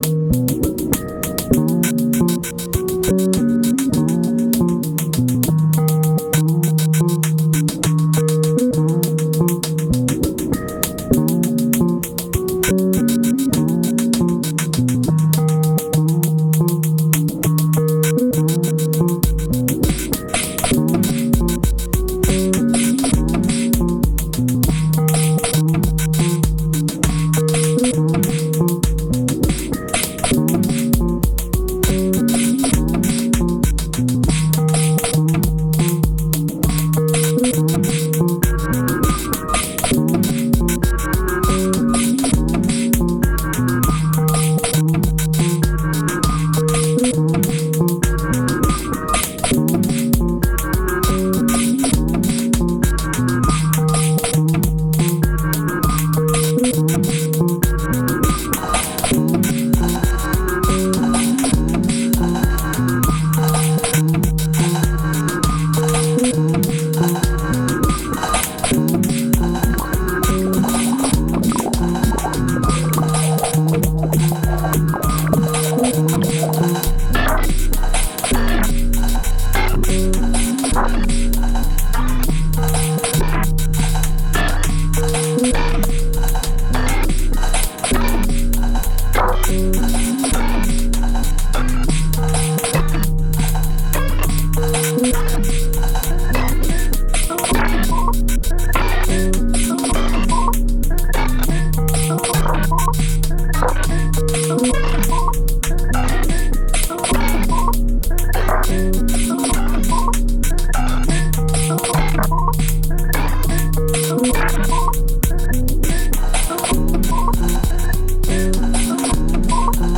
Excellent and extremely elegant electronic music.»